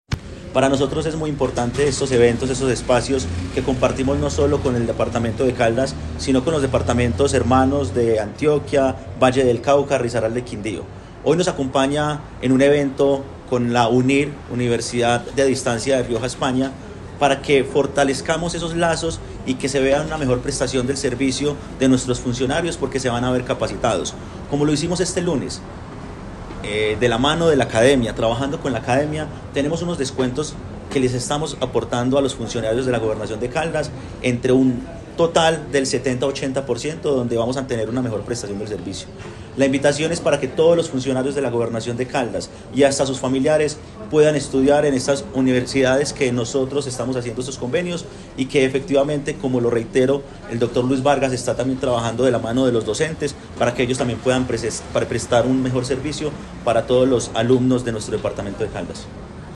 Secretario General de la Gobernación de Caldas, Juan Manuel Marín.